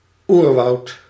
Ääntäminen
France (Paris): IPA: [la ʒœ̃ɡl]